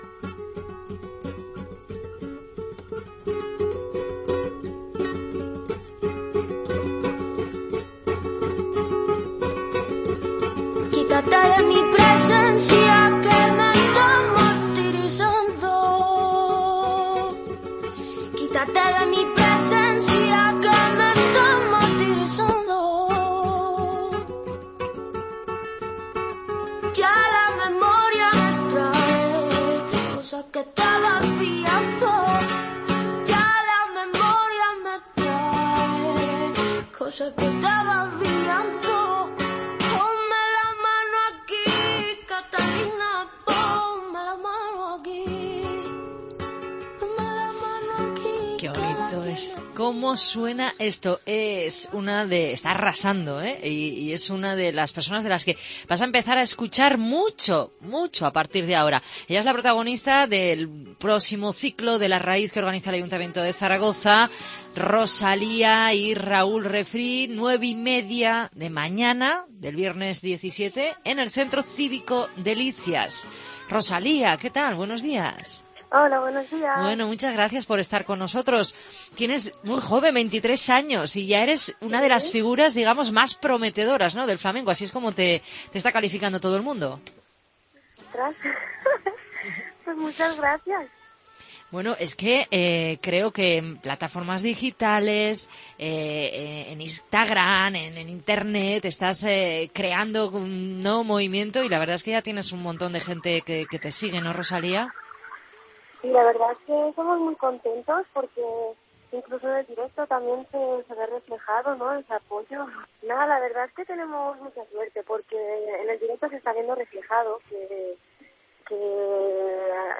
Entrevista a Rosalía en COPE Zaragoza. 16-02-17